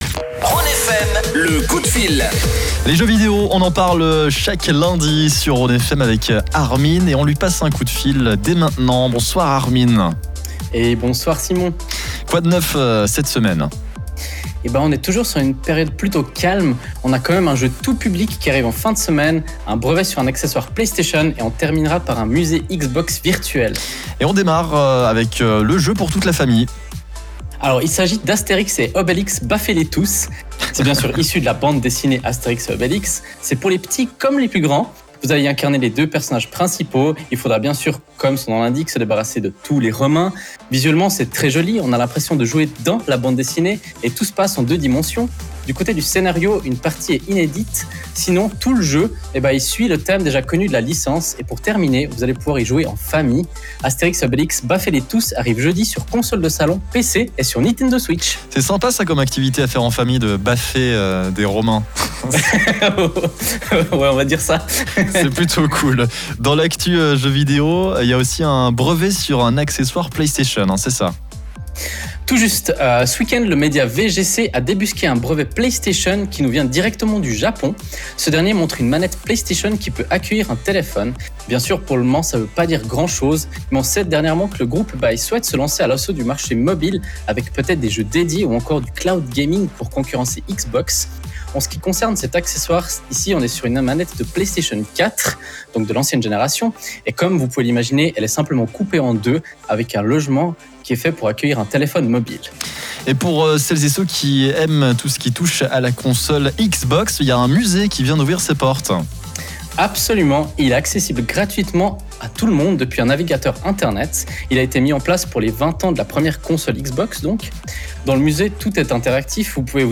Nous sommes lundi, et comme tous les lundis on fait un petit tour de l’actualité gaming du moment, sur la radio Rhône FM. Cette semaine c’est notre quinzième chronique et on se penche sur plusieurs sujets. Avant de vous lancer sur les résumés en dessous, voici le live rien que pour vous.